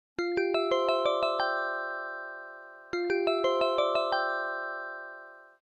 bubble 5